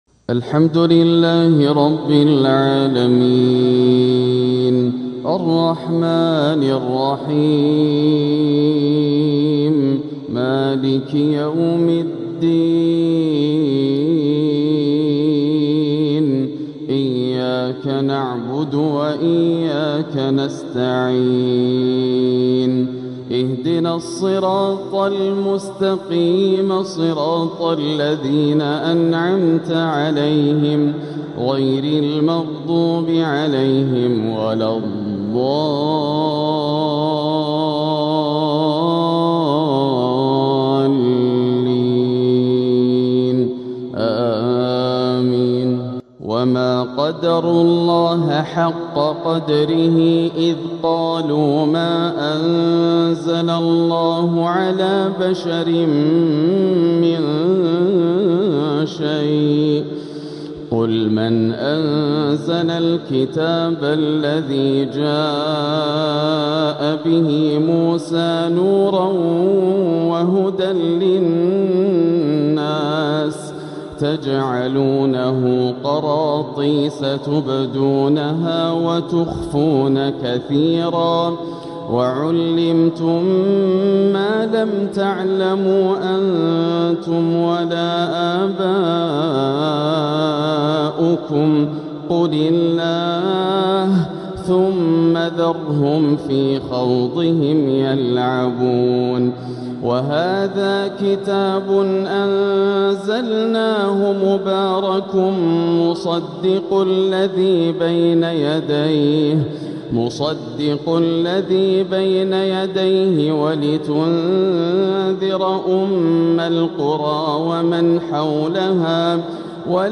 الآسر د. ياسر الدوسري في تحبير رهييب ومؤثر لتلاوات تاريخية | فجريات ٩ إلى ١٥ صفر ١٤٤٧هـ > الروائع > المزيد - تلاوات ياسر الدوسري